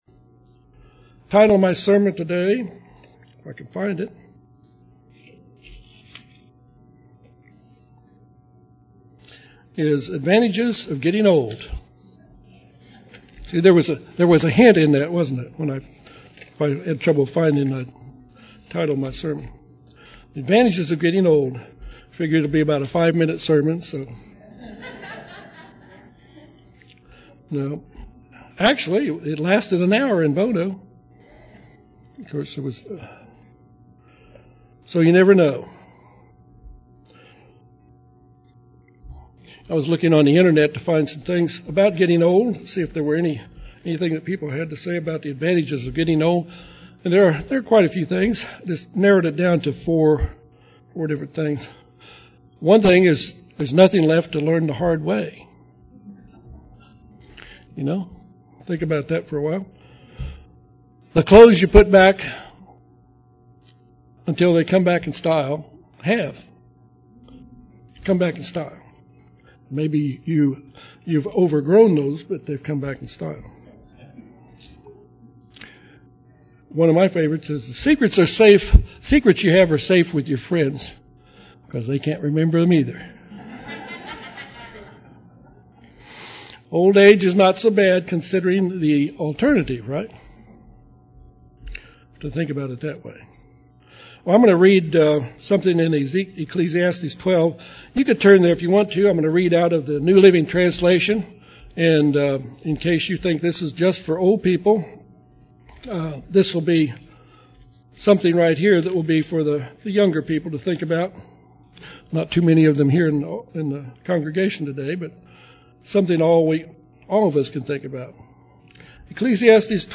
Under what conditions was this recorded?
Given in Little Rock, AR